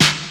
• Original Steel Snare Drum D Key 136.wav
Royality free snare one shot tuned to the D note. Loudest frequency: 2930Hz
original-steel-snare-drum-d-key-136-vXE.wav